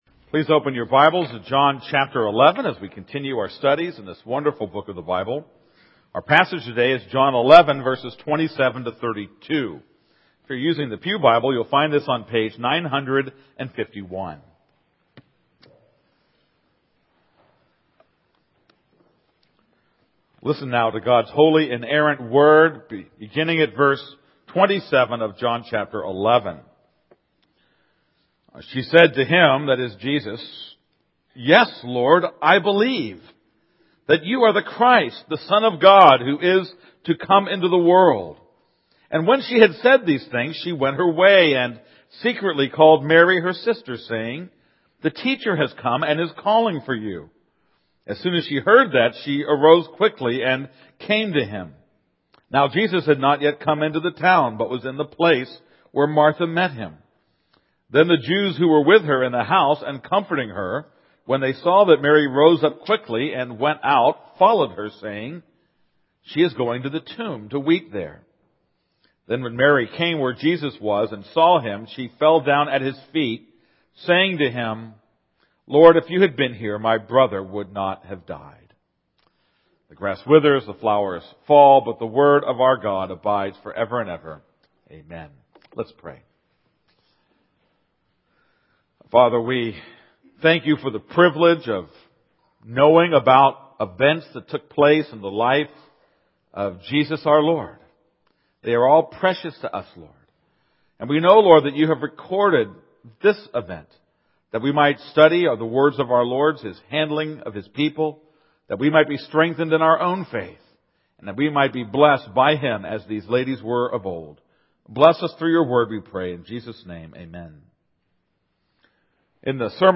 This is a sermon on John 11:27-32.